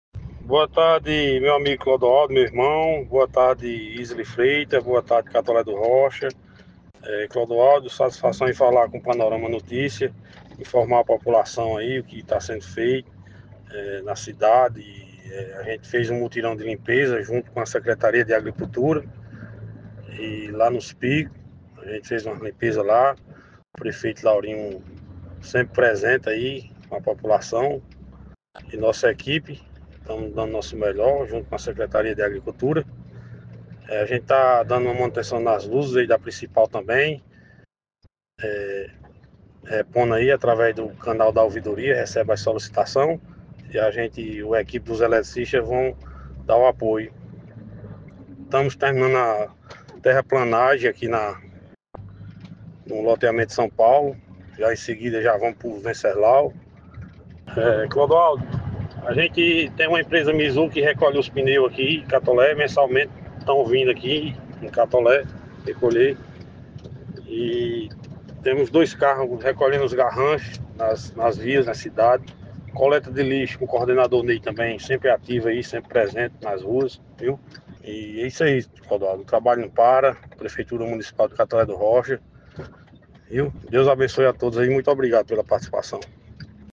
O secretário de Obras e Infraestrutura, Jilney Alves, traz mais informações sobre o mutirão e outros serviços realizados pela pasta.